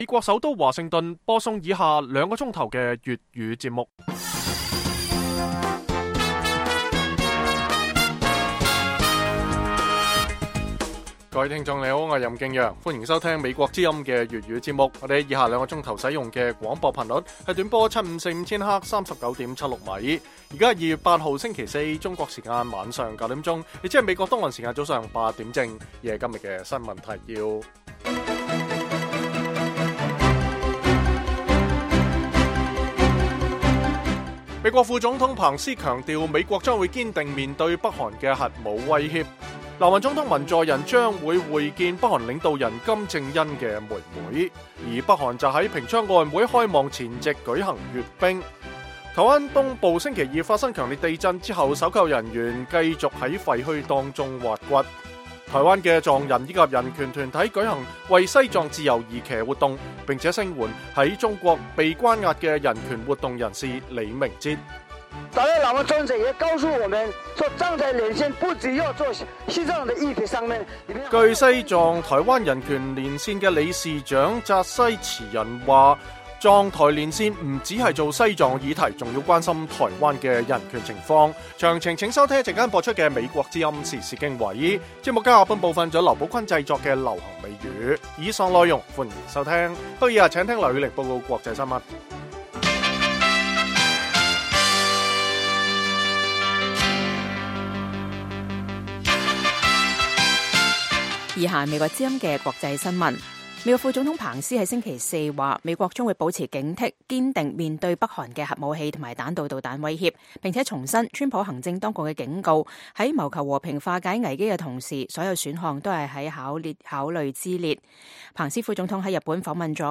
北京時間每晚9－10點 (1300-1400 UTC)粵語廣播節目。內容包括國際新聞、時事經緯和英語教學。